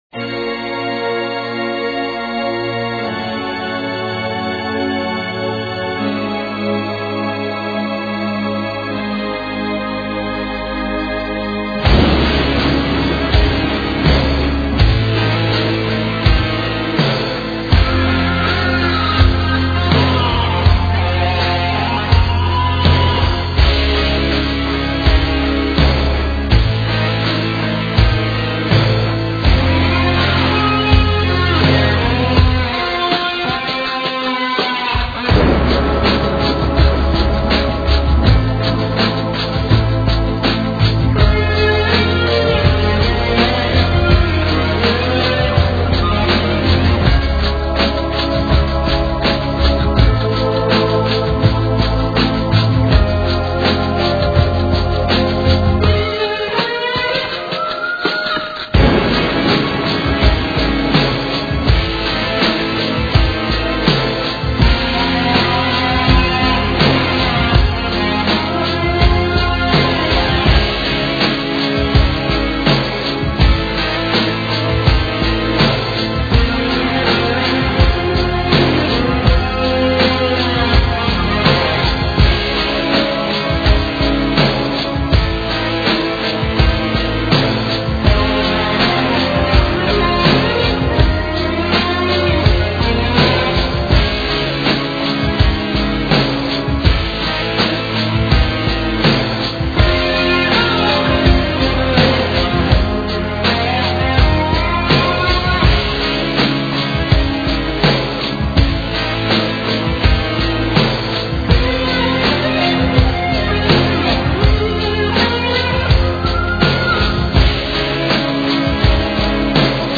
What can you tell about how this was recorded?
excellent quality